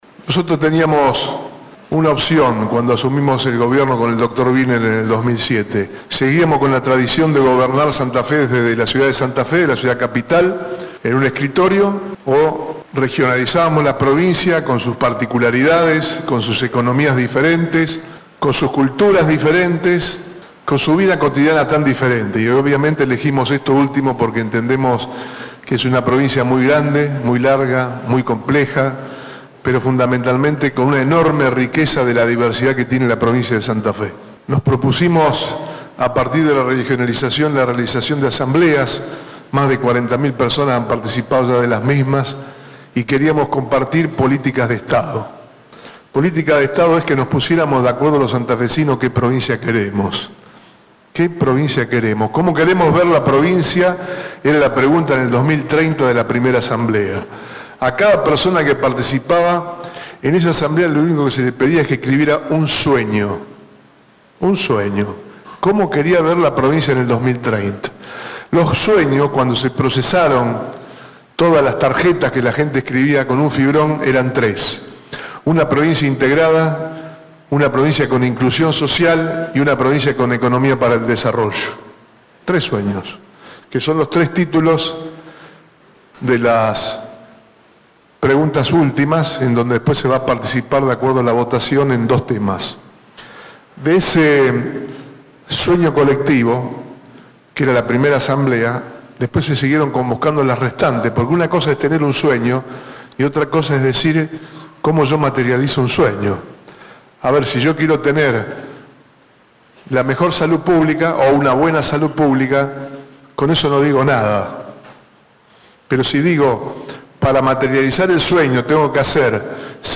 El gobernador presidió en Villa Constitución la tercera Asamblea Ciudadana de 2015.